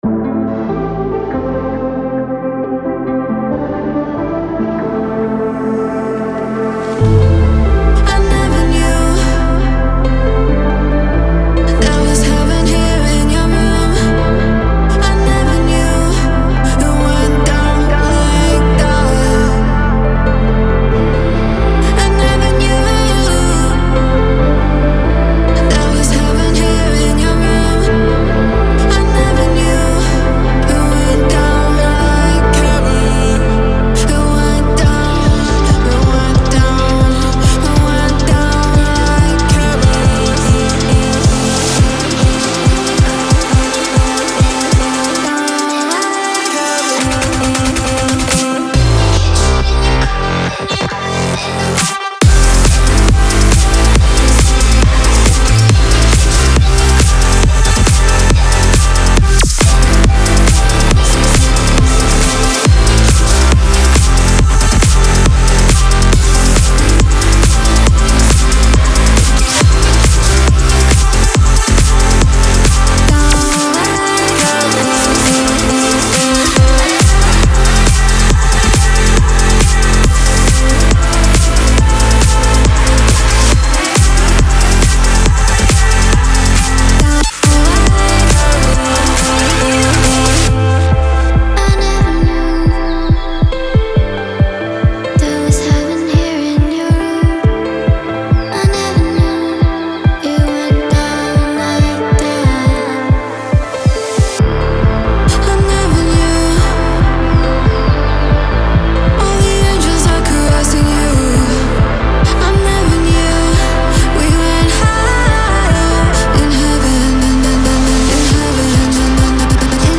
BPM138-138
Audio QualityPerfect (High Quality)
Full Length Song (not arcade length cut)